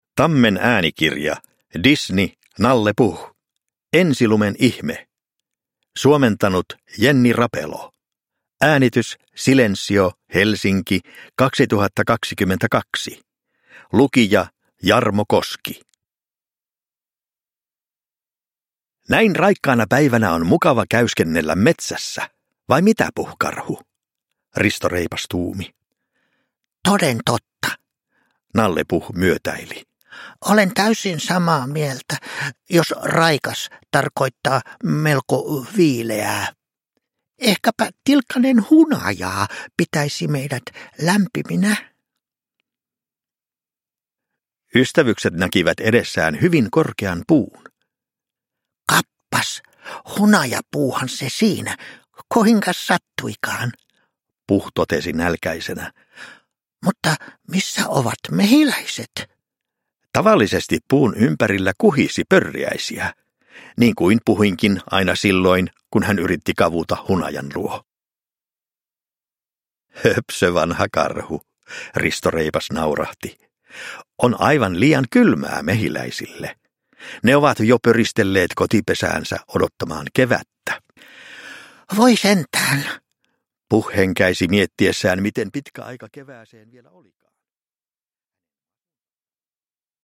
Nalle Puh. Ensilumen ihme – Ljudbok – Laddas ner